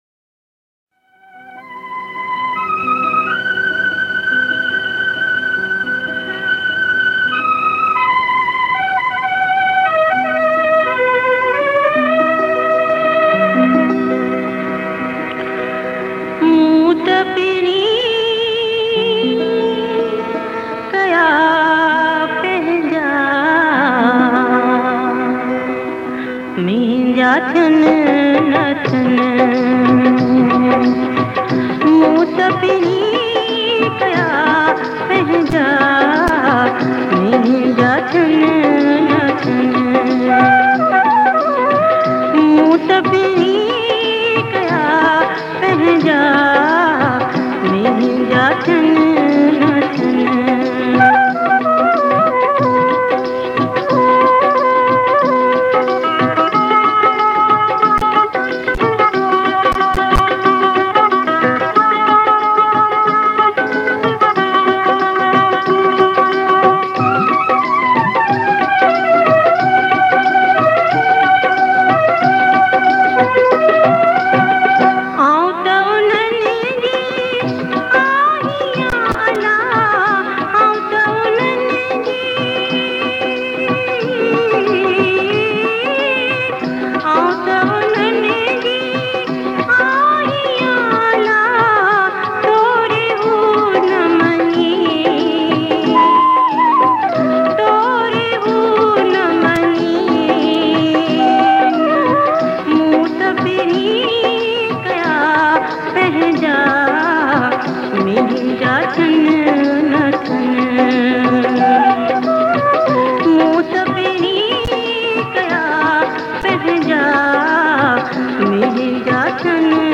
Sindhi Jhulelal Geet, Lada, Kalam, Ghazal & Bhajans